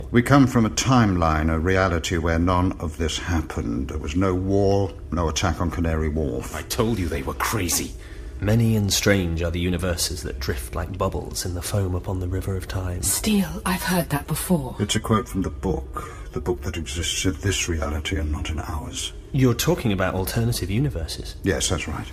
Audio drama
Several of the characters in this story are Americans, but the actors' accents aren't very convincing...they still sound British!
Memorable Dialog